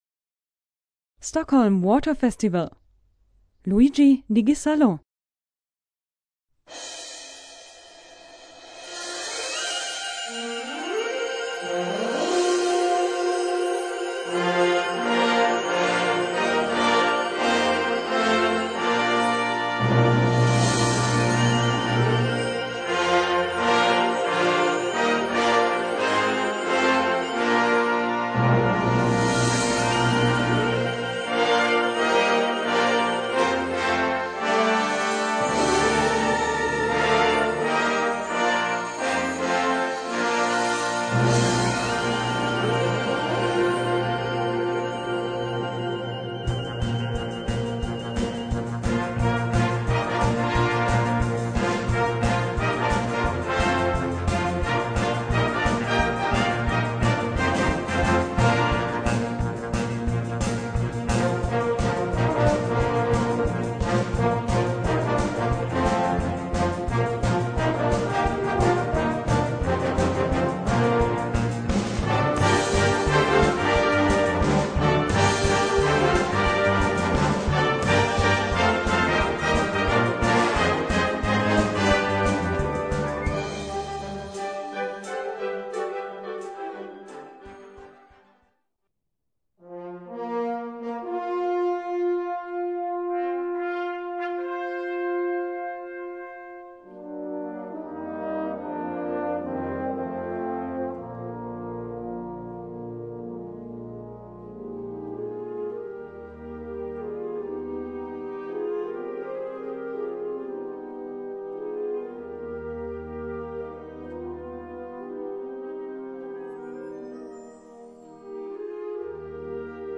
Gattung: Symphonic Rockoverture
Besetzung: Blasorchester